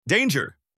space-game / public / assets / themes / default / audio / voice / danger.mp3
danger.mp3